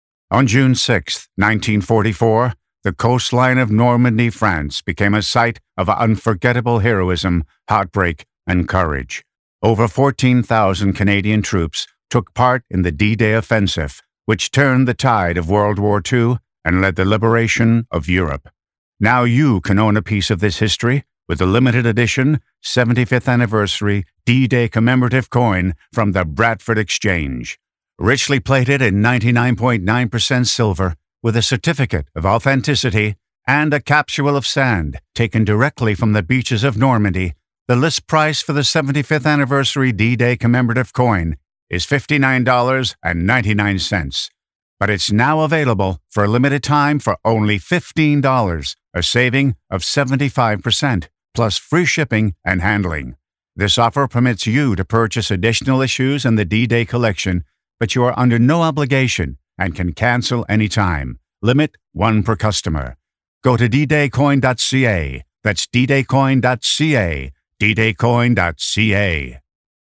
warm, emotive, diverse Male Voice Over Talent
I have a warm and sincere core baritone voice that can be dynamic and very emotive.
Television Spots D-Day Coin Tv Promo